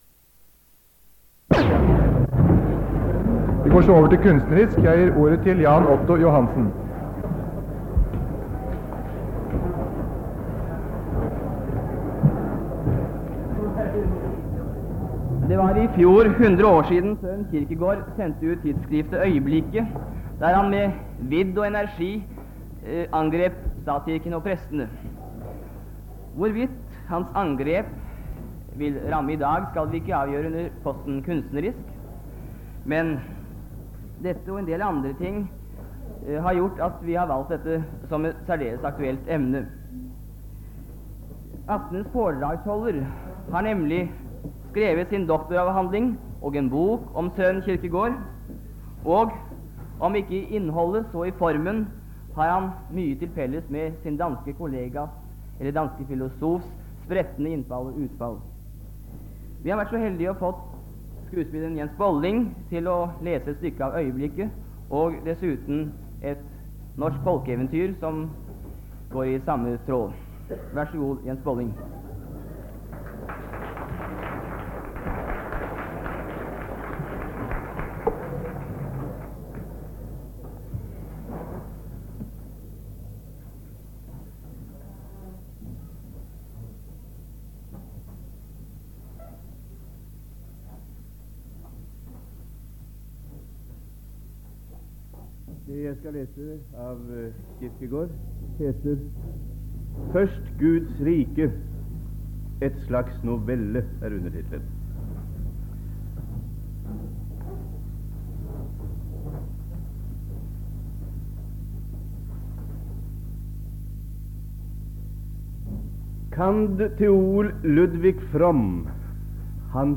Ub: Foredrag, debatter, møter